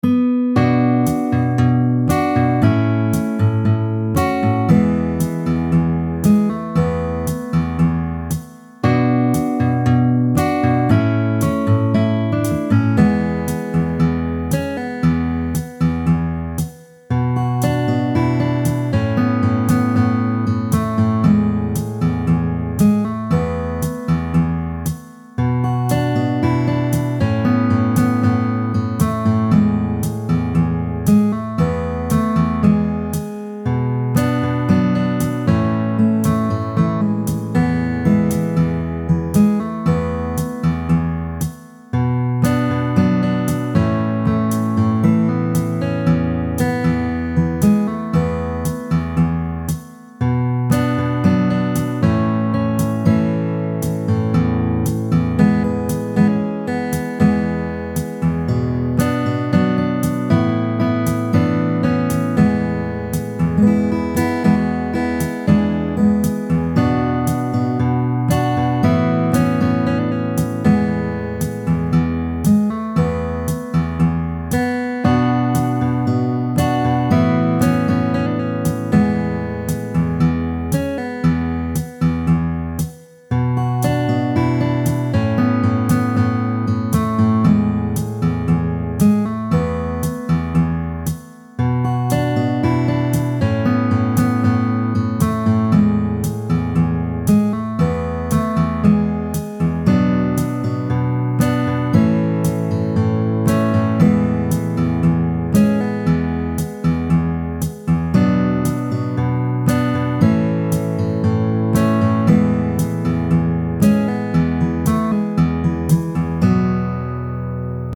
guitare acoustique
Accordage : Standard